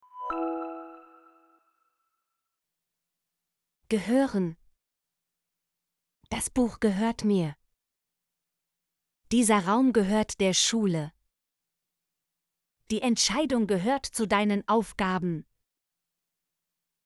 gehören - Example Sentences & Pronunciation, German Frequency List